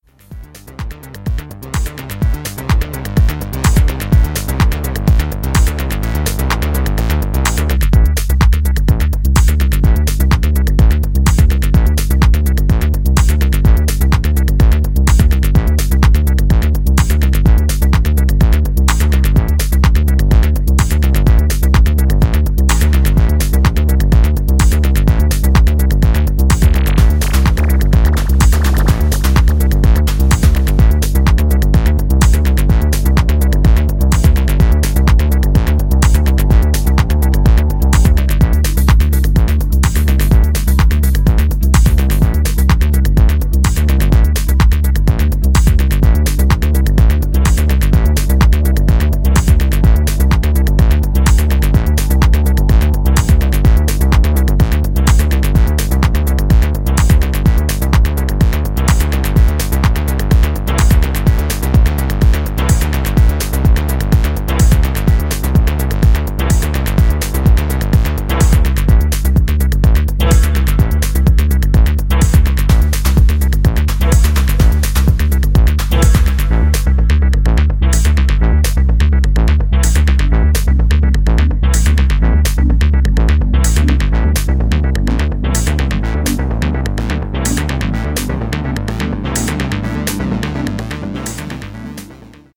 じっくりビルドアップするアシッド・ミニマル